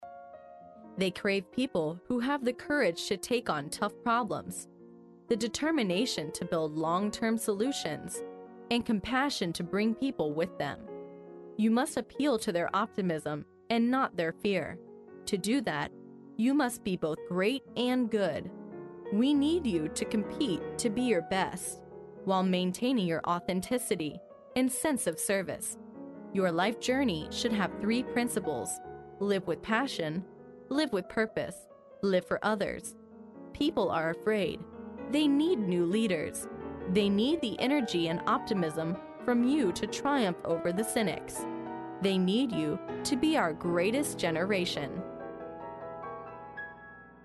在线英语听力室历史英雄名人演讲 第30期:成为我们的最伟大一代(2)的听力文件下载, 《历史英雄名人演讲》栏目收录了国家领袖、政治人物、商界精英和作家记者艺人在重大场合的演讲，展现了伟人、精英的睿智。